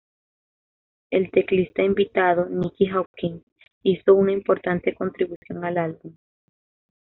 /kontɾibuˈθjon/